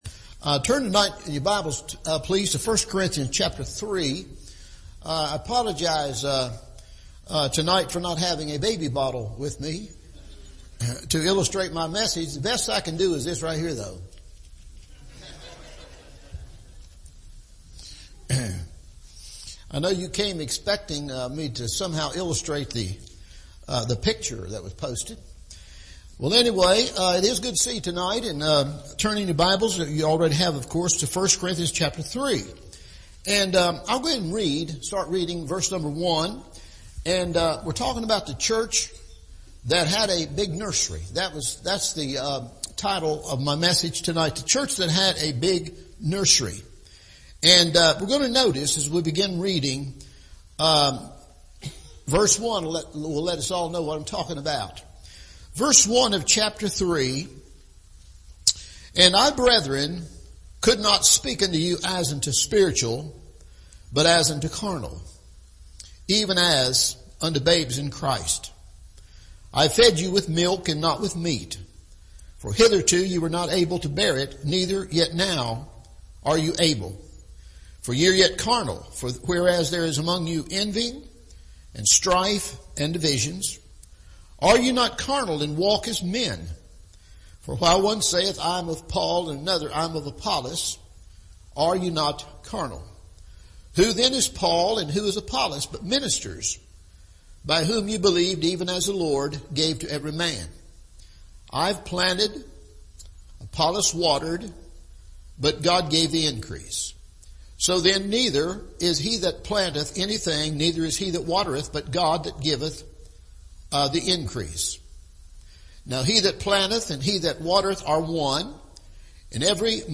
The Church with A Big Nursery – Evening Service